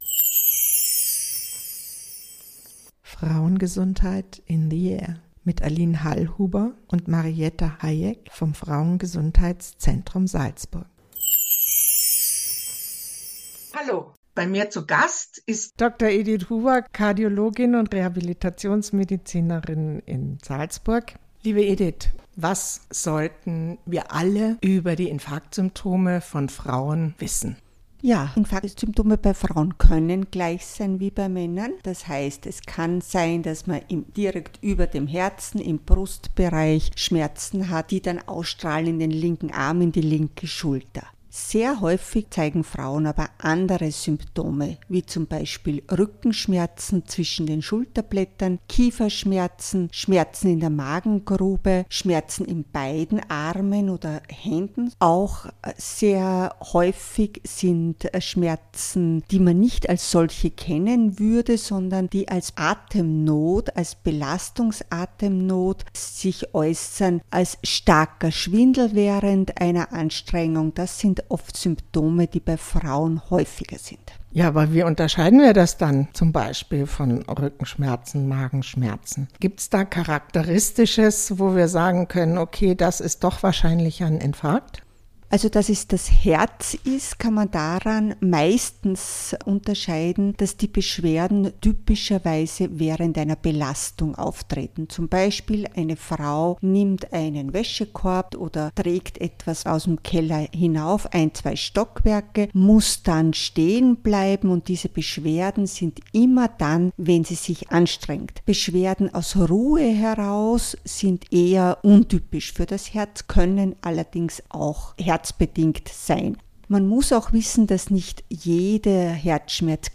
Im Gespräch mit der Kardiologin und Internistin